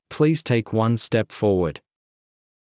ﾌﾟﾘｰｽﾞ ﾃｲｸ ﾜﾝ ｽﾃｯﾌﾟ ﾌｫｰﾜｰﾄﾞ